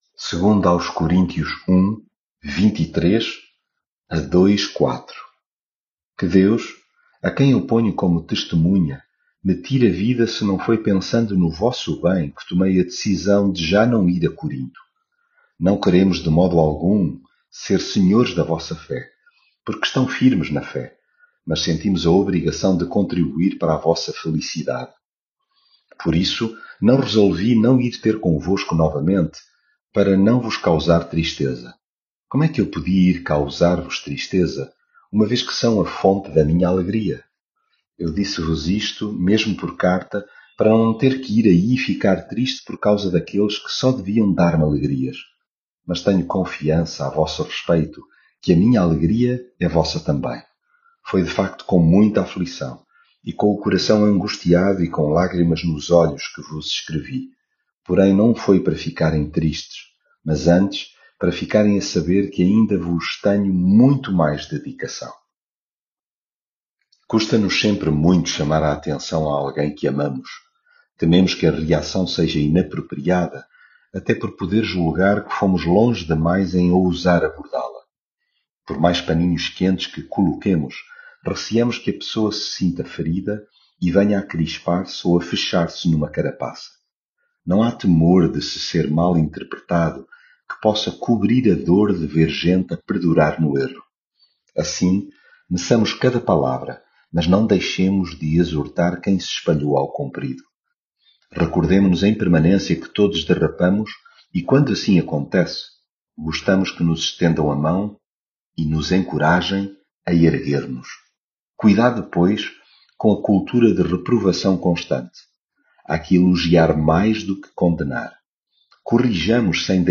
devocional coríntios leitura bíblica Que Deus, a quem eu ponho como testemunha, me tire a vida se não foi pensando no vosso bem que tomei...